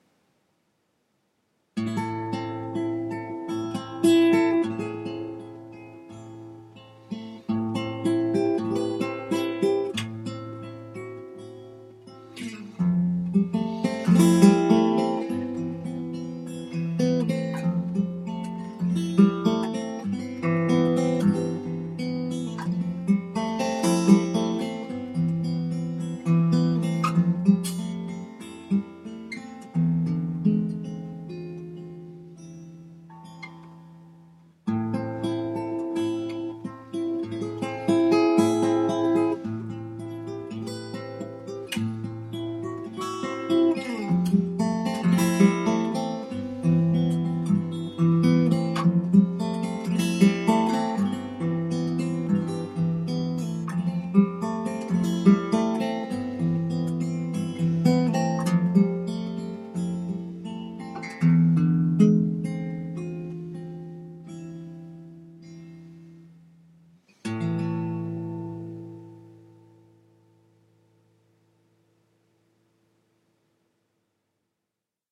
composed an accompanying score for acoustic guitar to run alongside the image projection and reading